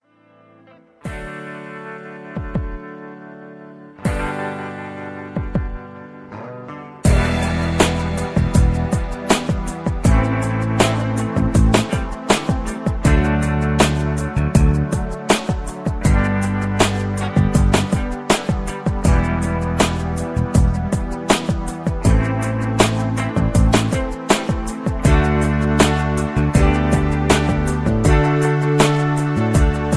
Just Plain & Simply "GREAT MUSIC" (No Lyrics).
mp3 backing tracks